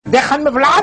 Mensaje / Tonos Divertidos
Este tono es ideal para mensajes ya que es corto y gracioso.